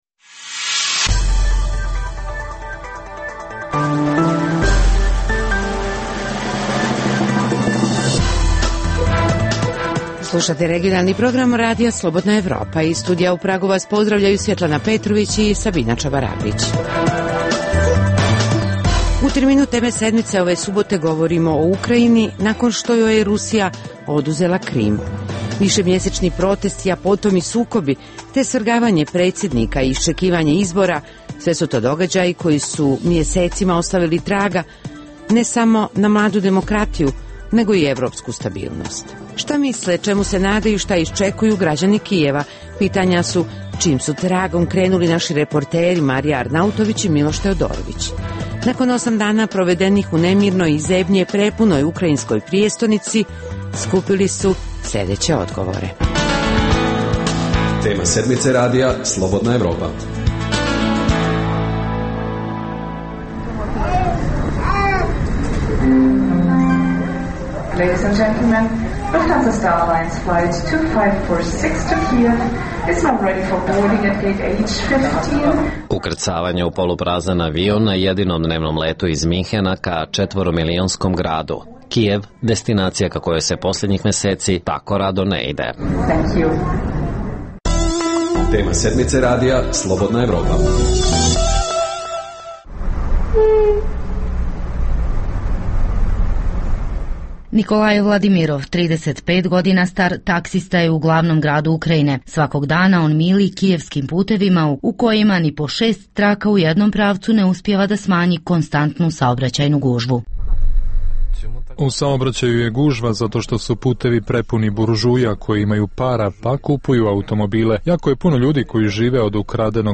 Emisija o dešavanjima u regionu (BiH, Srbija, Kosovo, Crna Gora, Hrvatska) i svijetu. Prvih pola sata emisije sadrži regionalne i vijesti iz svijeta, te temu sedmice u kojoj se analitički obrađuju najaktuelnije i najzanimljivije teme o dešavanjima u zemljama regiona.